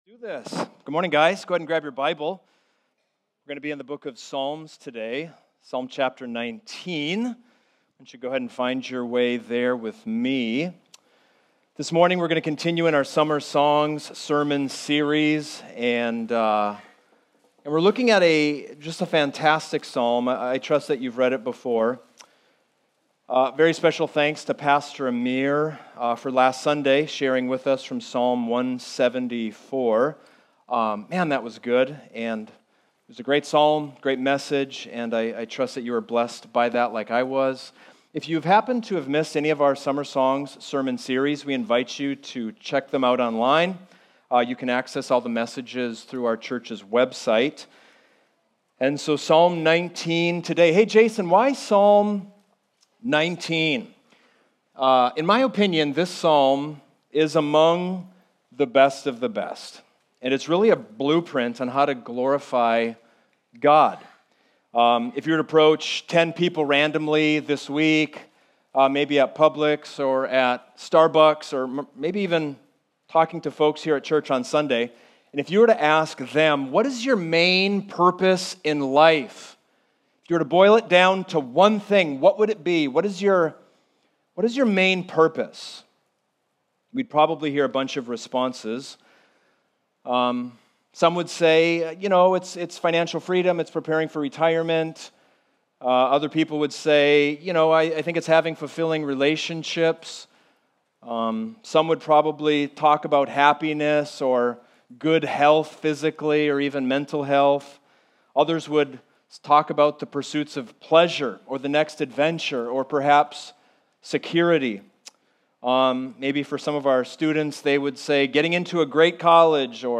Home Sermons Summer Psalms 2022